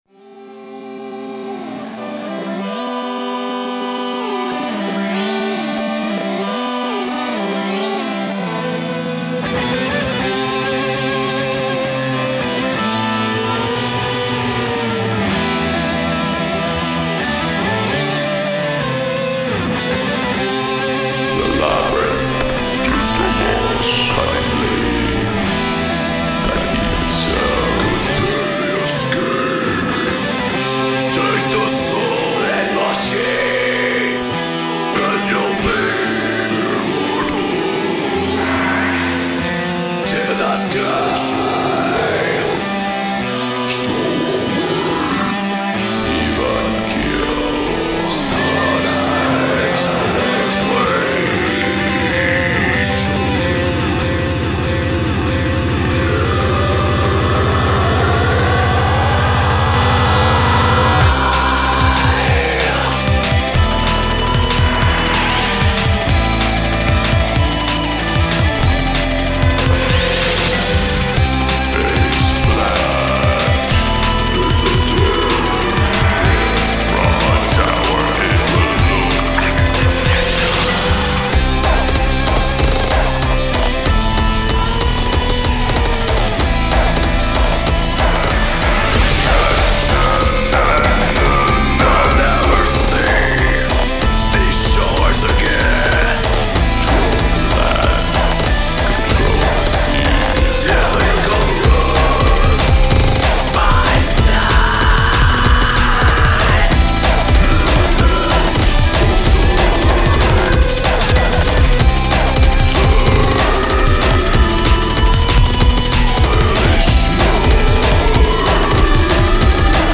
3 Aug LA's Electro Bend
It’s fun, clever and good.